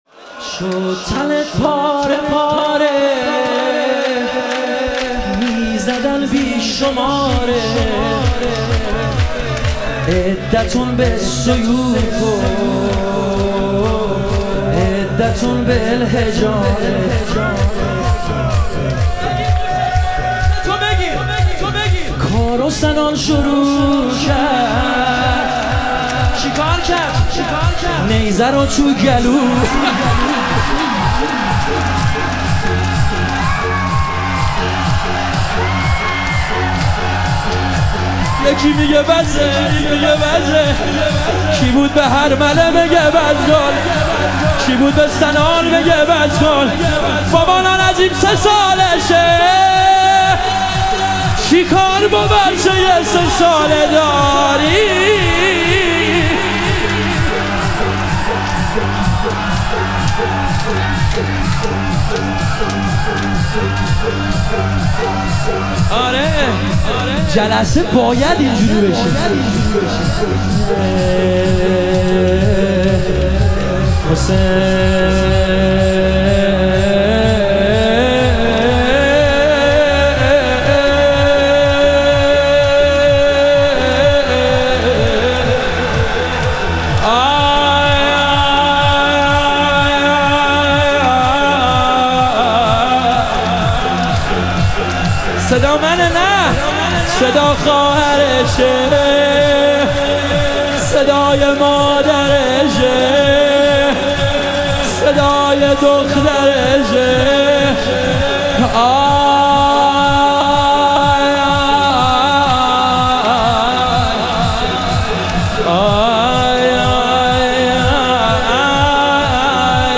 شهادت امام کاظم علیه السلام - لطمه زنی